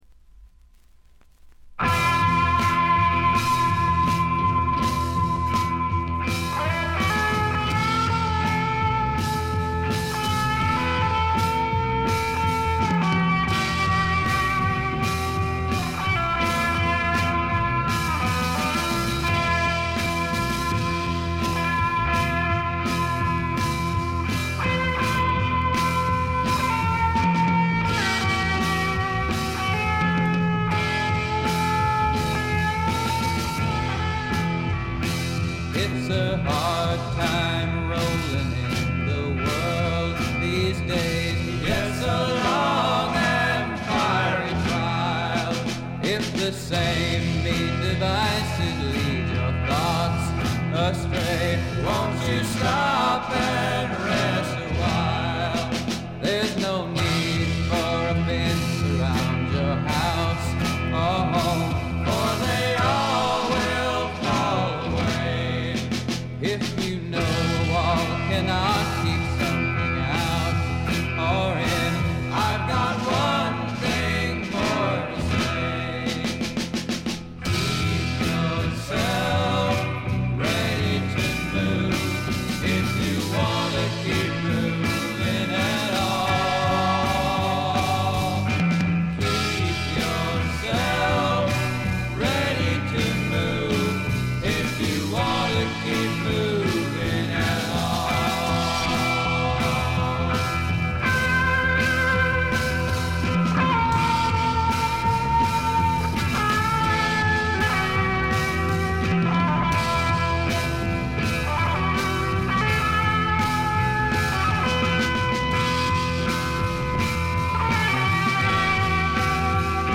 静音部での軽微なバックグラウンドノイズ程度。
素晴らしいサイケデリック名盤です。
試聴曲は現品からの取り込み音源です。
Recorded At - Sound City Inc, Recording Studios